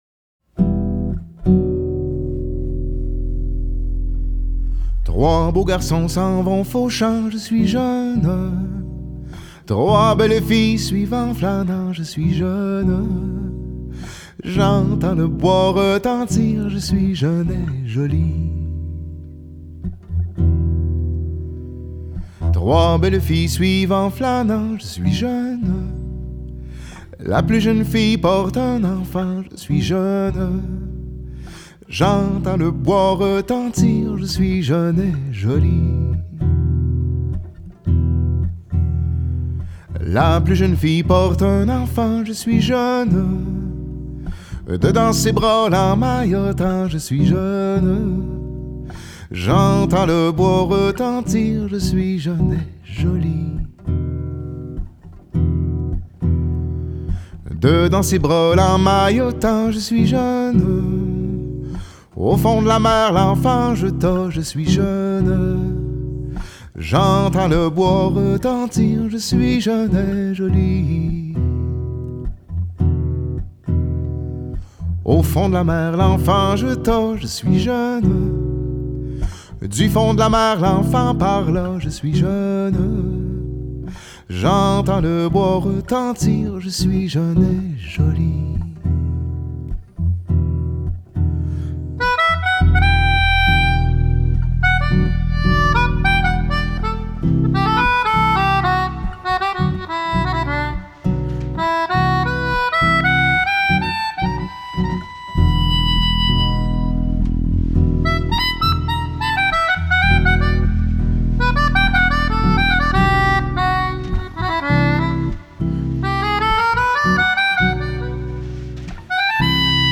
double bassist